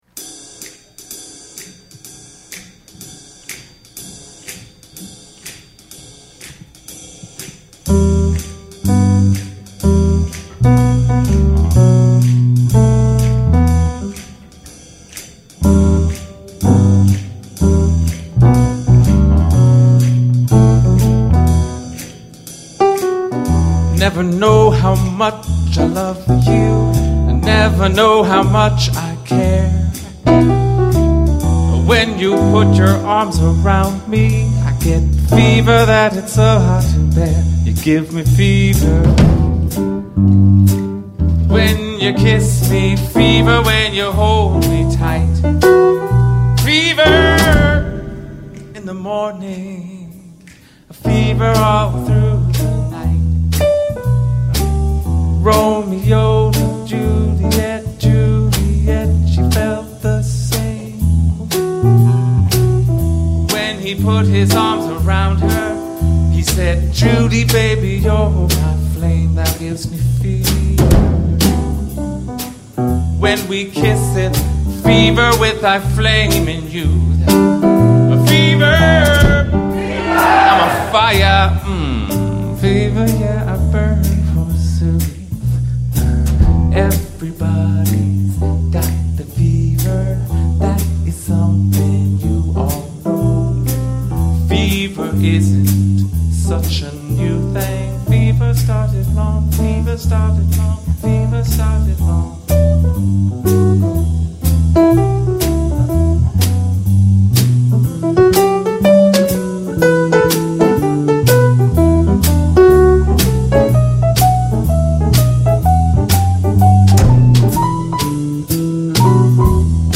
Recorded at ACT Theatre on April 27, 2015.
Piano
Bass
Harmonica
Guitar
Drums